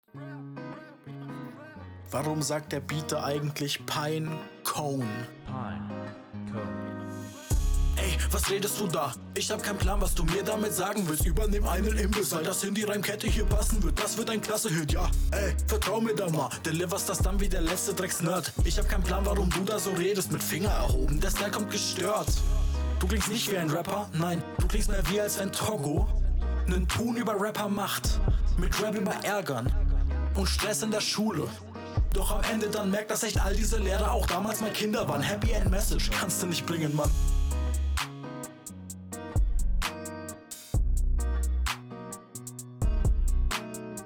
warum ist das nur auf links? das nimmt jeden möglichen hörgenuss